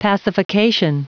Prononciation du mot pacification en anglais (fichier audio)
Prononciation du mot : pacification